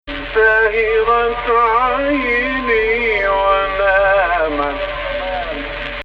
The Final 3 sentence types here use the jins underneath the root tonic of the maqam, which as you can see by the lack of examples is actually quite rare, at least in this period.
Type: 2nd Rast => bass octave 765